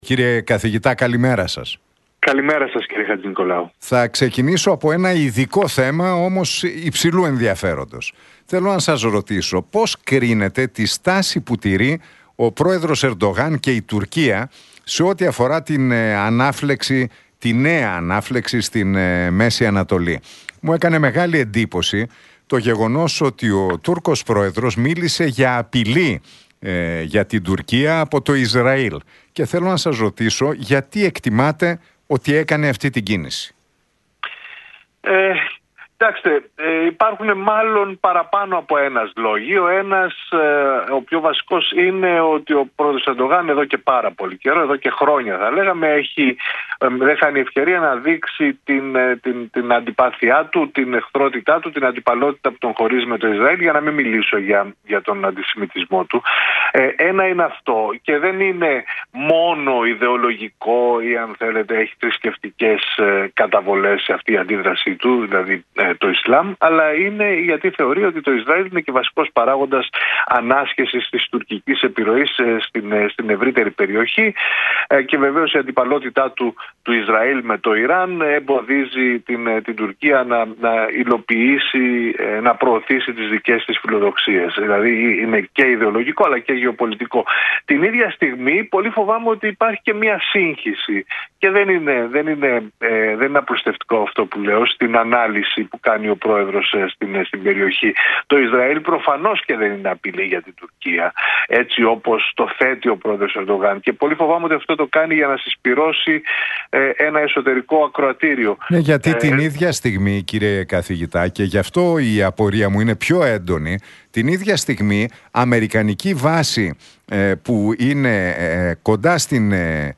στον Realfm 97,8 και την εκπομπή του Νίκου Χατζηνικολάου.